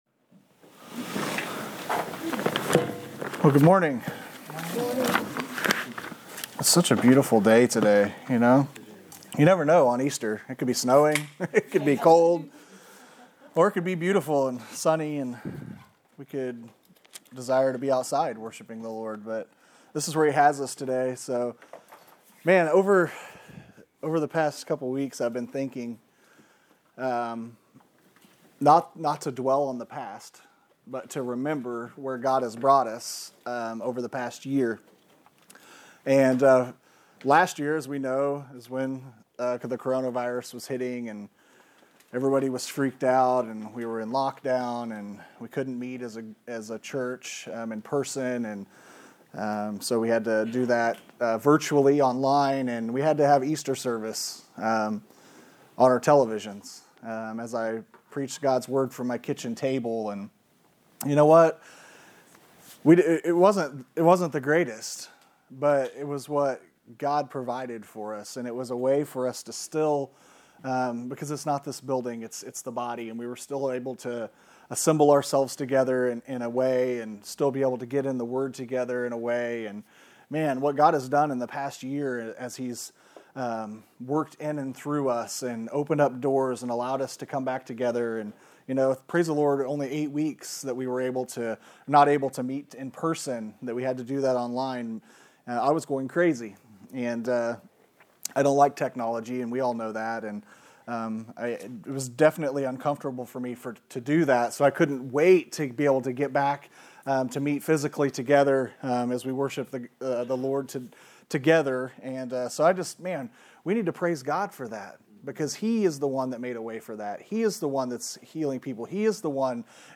Easter Service 2021